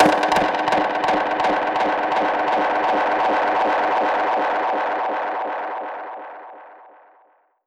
Index of /musicradar/dub-percussion-samples/125bpm
DPFX_PercHit_D_125-02.wav